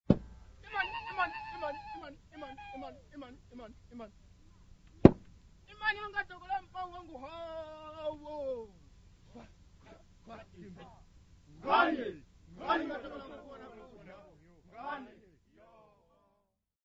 ATC034a-06.mp3 of Ngeniso with drum 1979